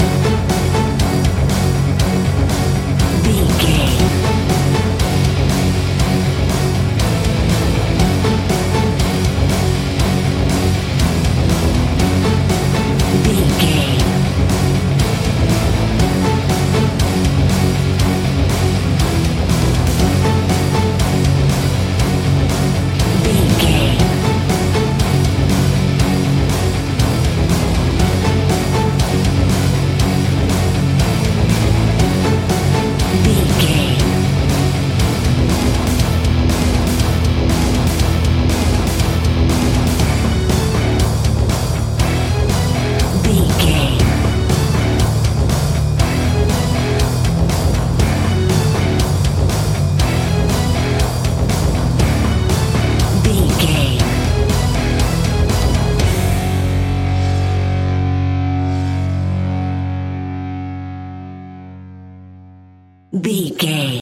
Epic / Action
Fast paced
Aeolian/Minor
F#
hard rock
heavy metal
horror rock
instrumentals
Heavy Metal Guitars
Metal Drums
Heavy Bass Guitars